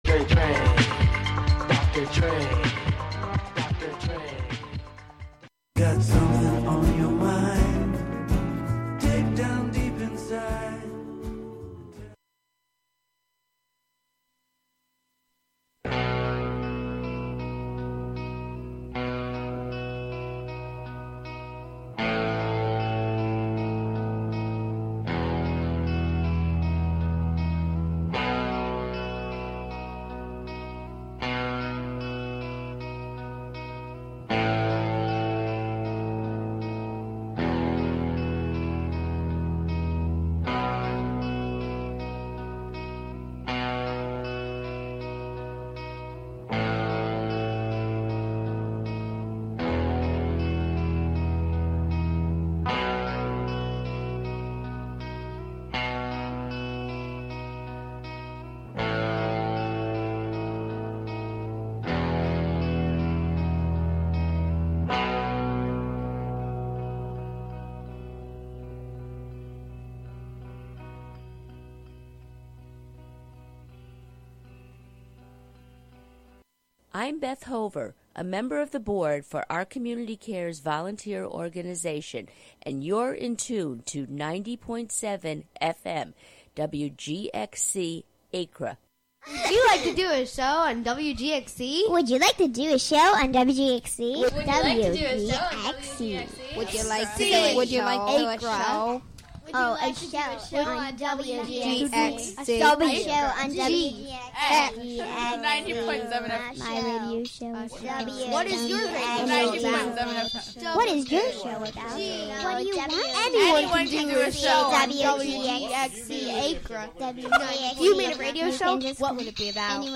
I like to start them out at 125 bpm and just keep building.